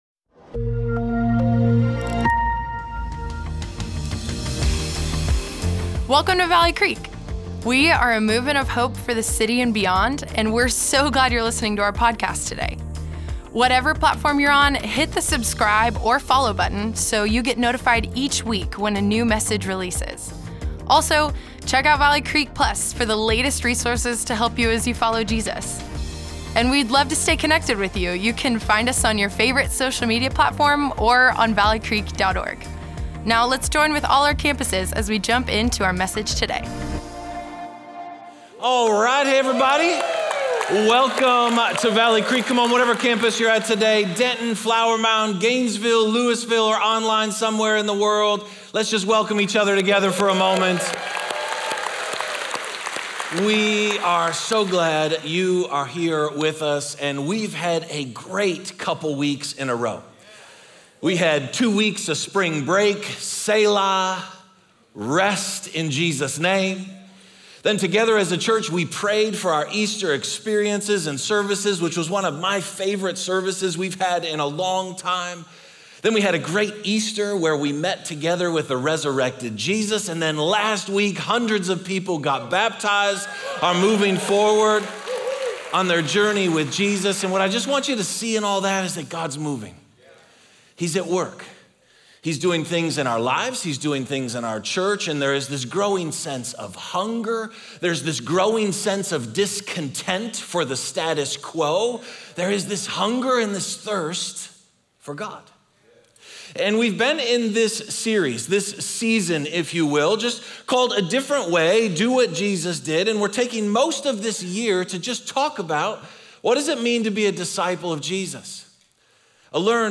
Valley Creek Church Weekend Messages Meditation, Part One Apr 14 2024 | 00:51:57 1x 00:00 / 00:51:57 Subscribe Share Apple Podcasts Spotify Amazon Music Overcast RSS Feed Share Link Embed